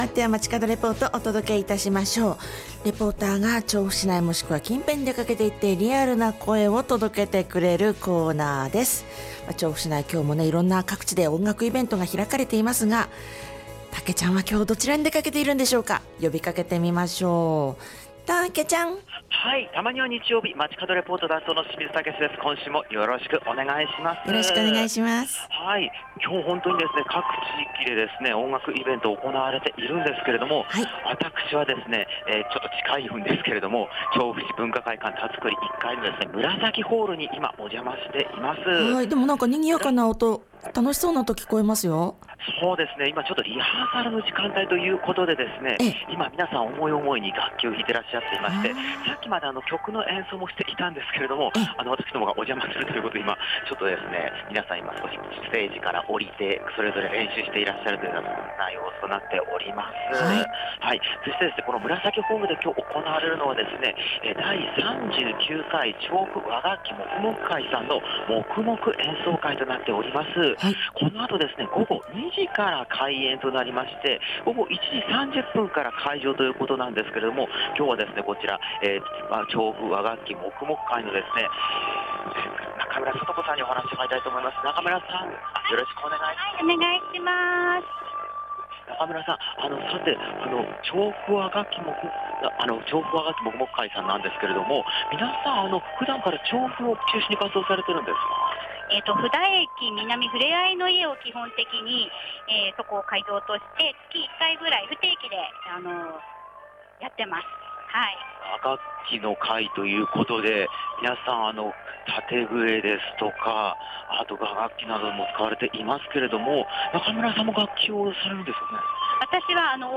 40℃予想でしたが、雲が出ているおかげで少しだけ過ごしやすい空の下からお届けした本日の街角レポートは、